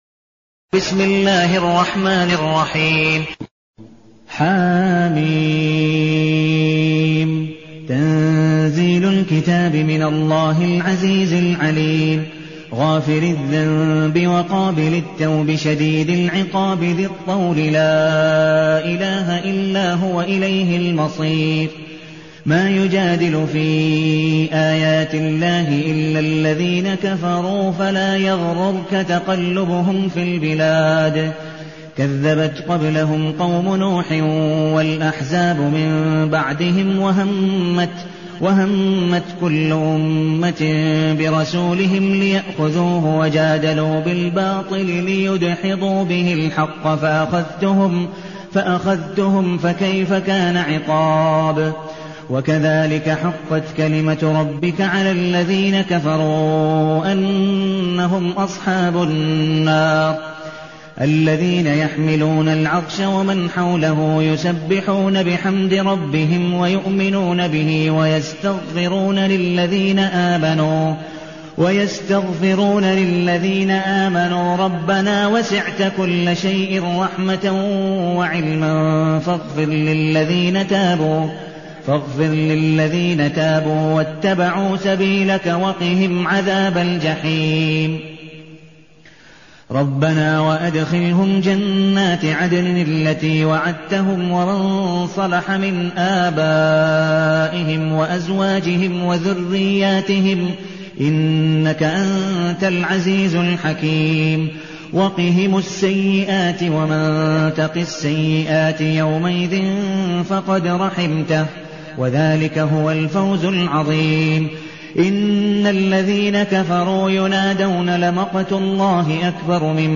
المكان: المسجد النبوي الشيخ: عبدالودود بن مقبول حنيف عبدالودود بن مقبول حنيف غافر